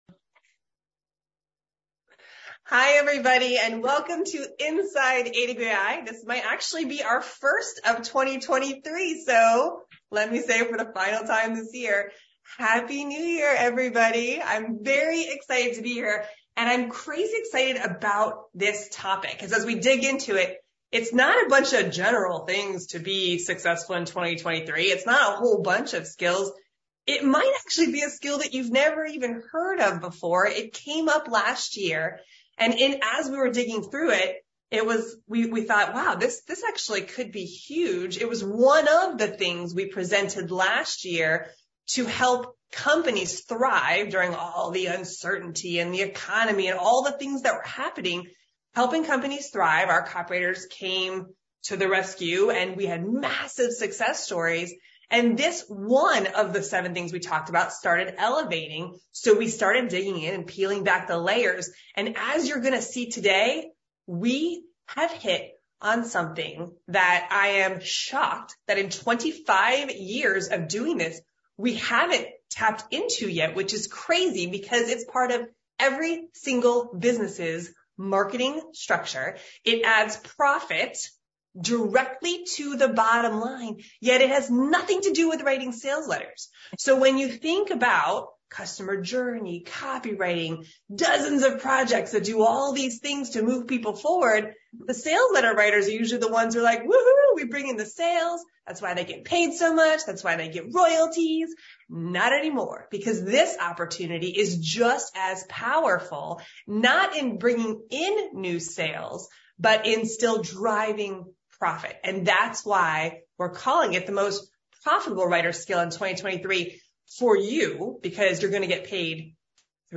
Inside AWAI Webinar and Q&A: Thrive as a Freelance Writer in 2023 with the Skill Every Company Needs Right Now